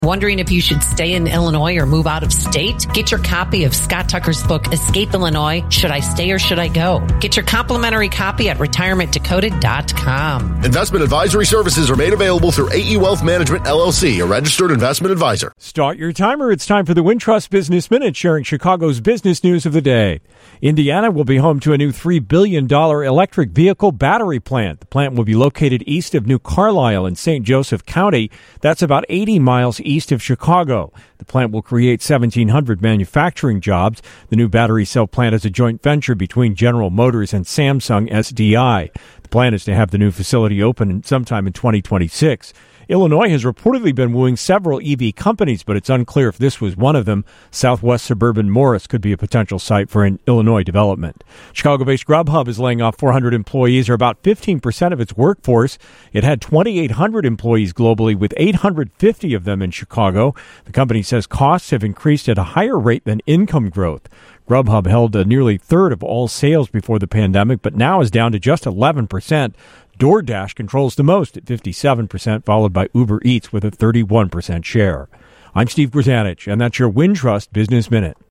business news of the day